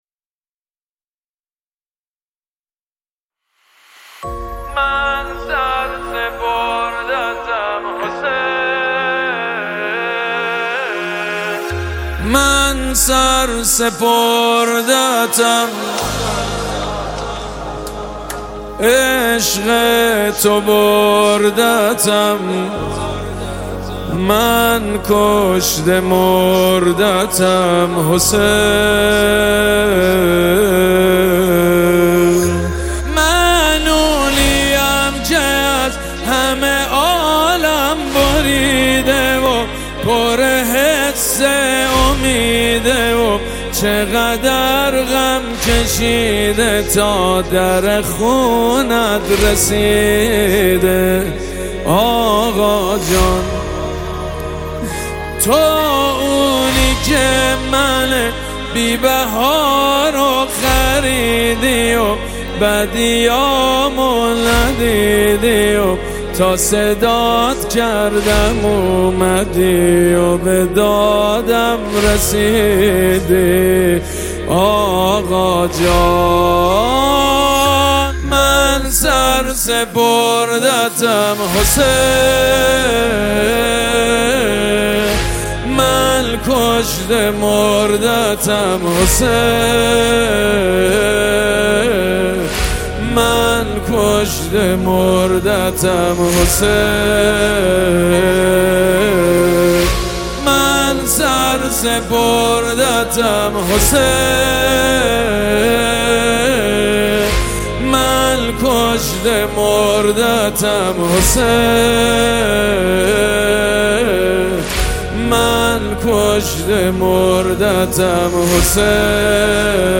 نماهنگ ماه محرم
نماهنگ مذهبی